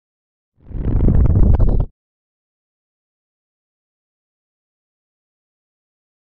Liquid Hit Slow Liquid Rip